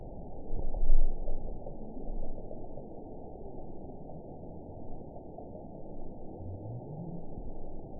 event 922179 date 12/27/24 time 22:27:05 GMT (5 months, 3 weeks ago) score 9.42 location TSS-AB06 detected by nrw target species NRW annotations +NRW Spectrogram: Frequency (kHz) vs. Time (s) audio not available .wav